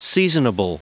Prononciation du mot seasonable en anglais (fichier audio)
Prononciation du mot : seasonable